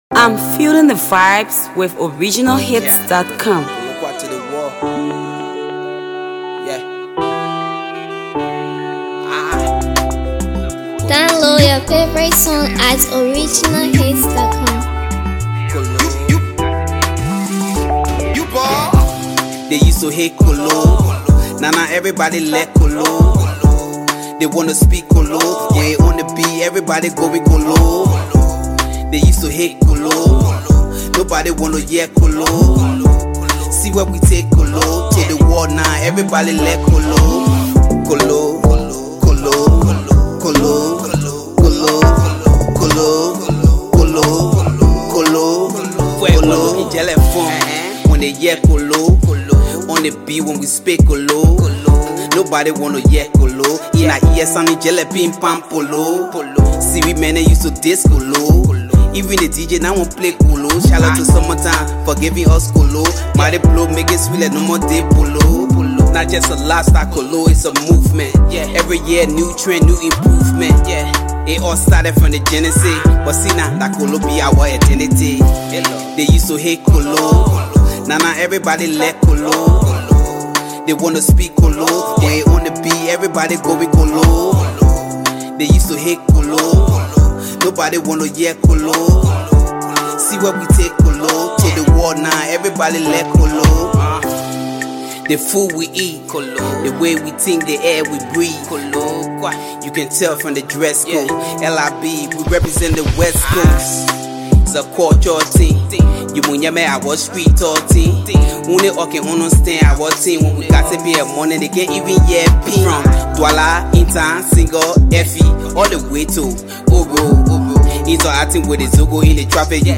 Sensational Liberian Hipco rapper